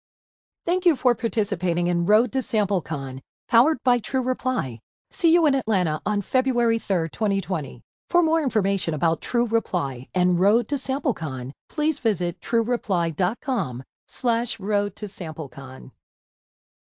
- Voice Ambassadors™ recording the messaging
The entire process from idea to launch took about 4 days including the custom recordings by a professional Voice Ambassador™ and the approval process.
Road_to_samplecon_thankyou.mp3